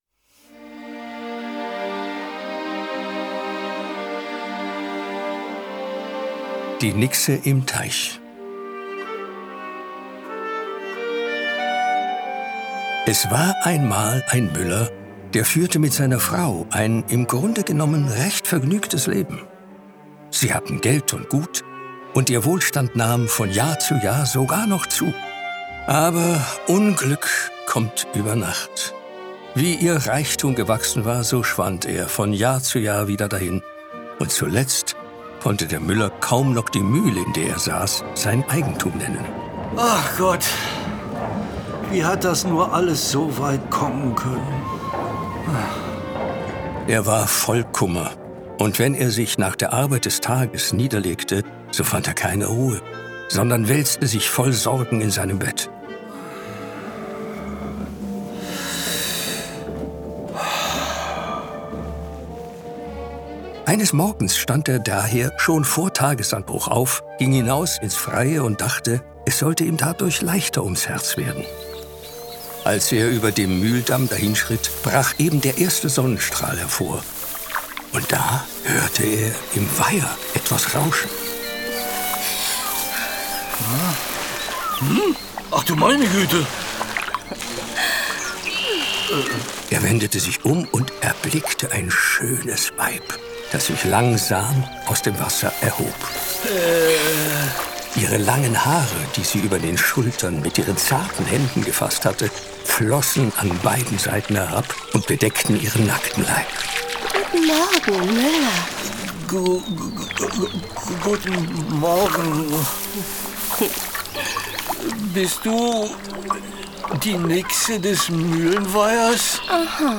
Drei Hörspiele